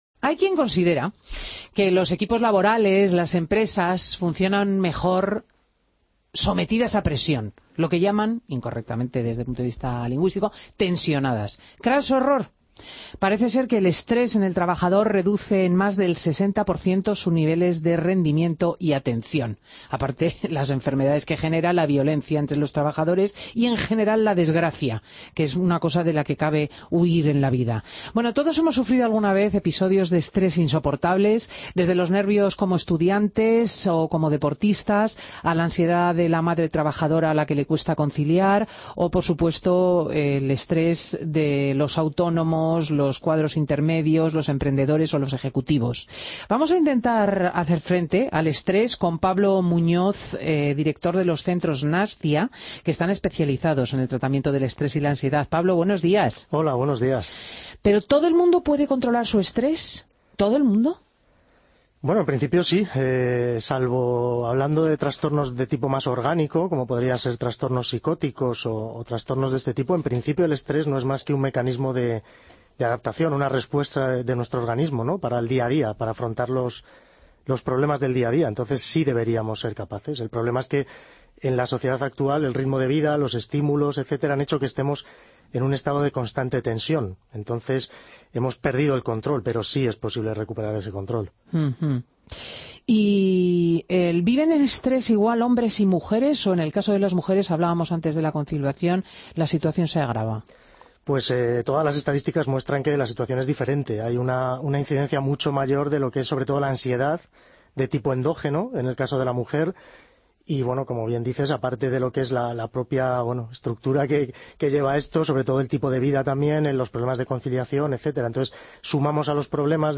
Entrevistas en Fin de Semana